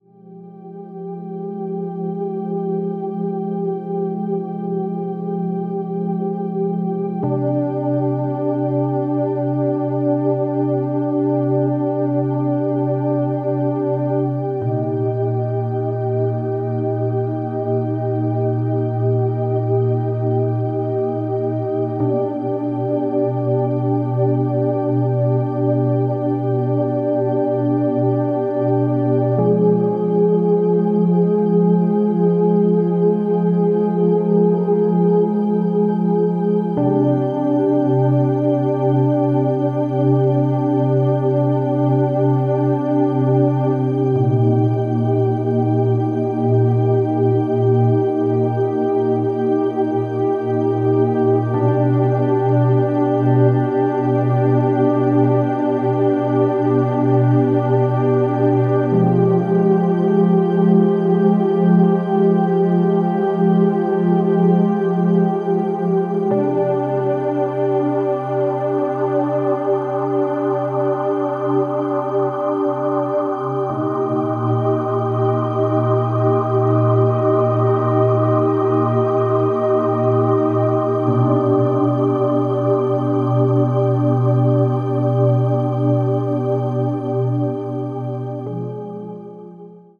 electronic album